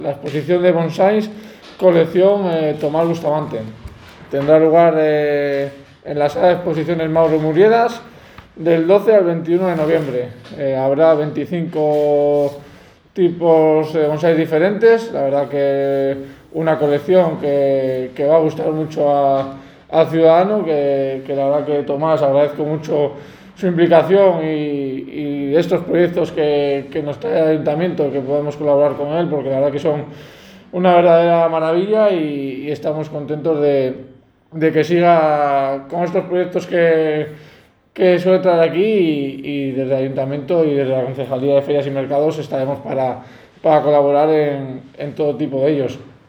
Audio del Concejal Nacho González.